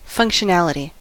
functionality: Wikimedia Commons US English Pronunciations
En-us-functionality.WAV